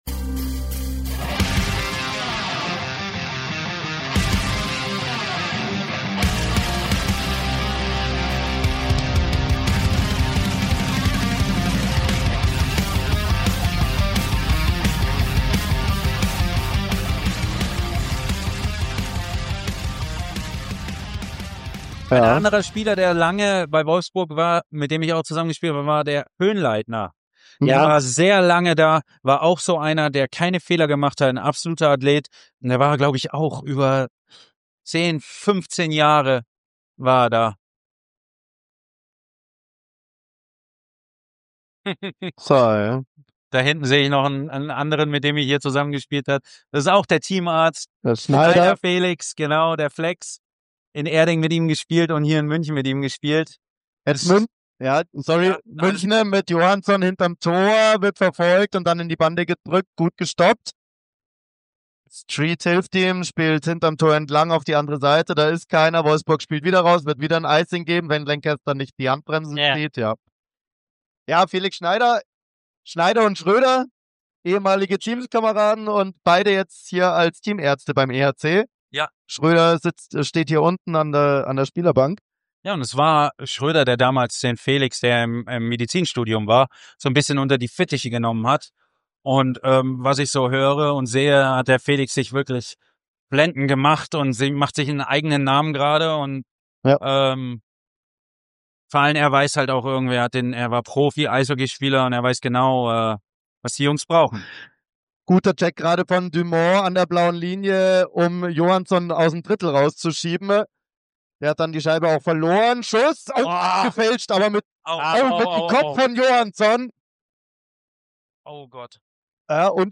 In dieser besonderen Episodenreihe nehmen wir euch mit zurück ins Jahr 2024 – direkt in die Kommentatorenkabine des Oly!
Original-Radioübertragung aus dem Jahr 2024